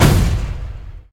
wall.ogg